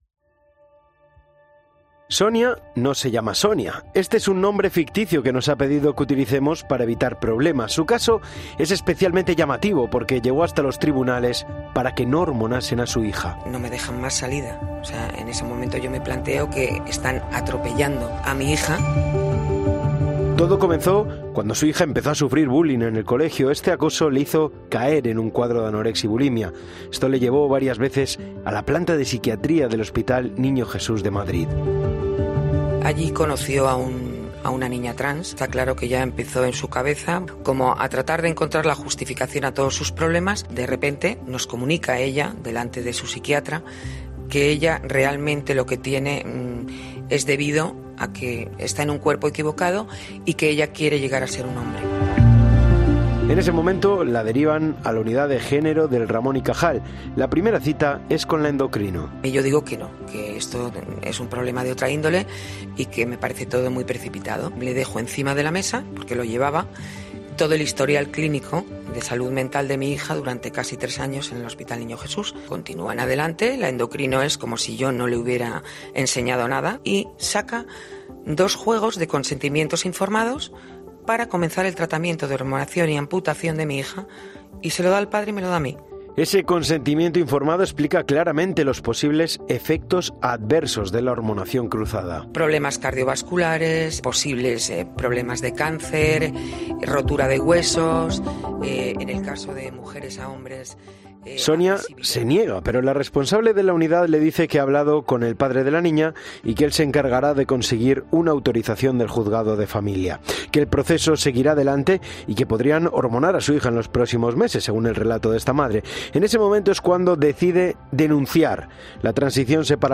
INFORME COPE sobre La ley Trans